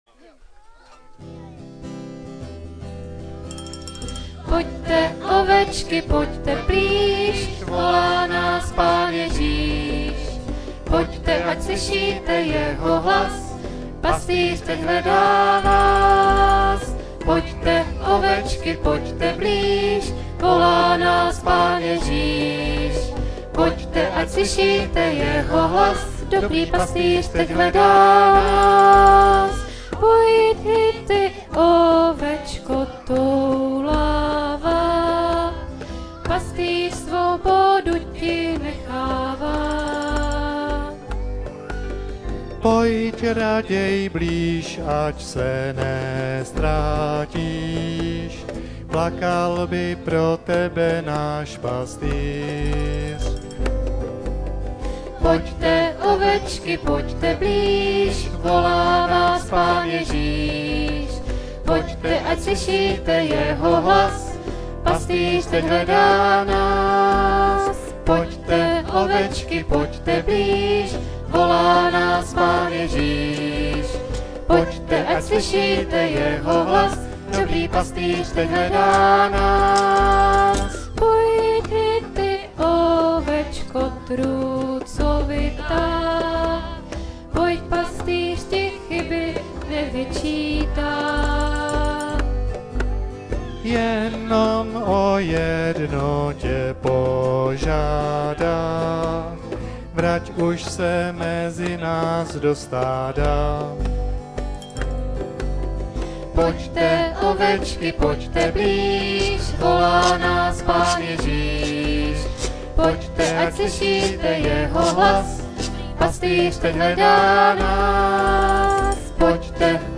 koncert.mp3